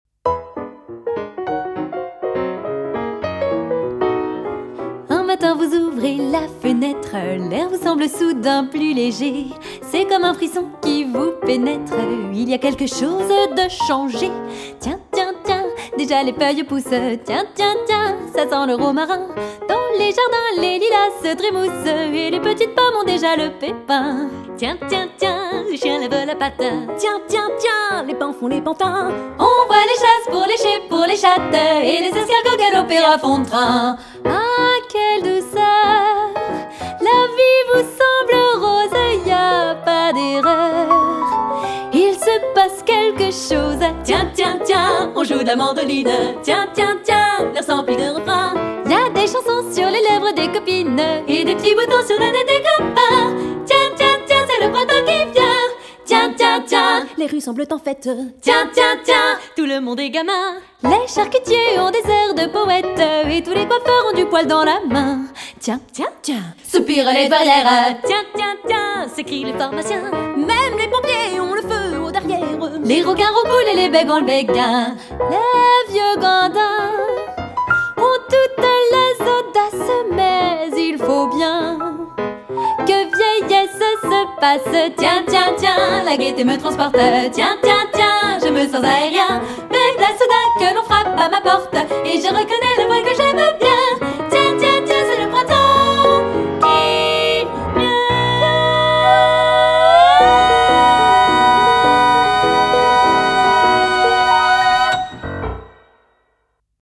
Musique théâtralisée.
Piano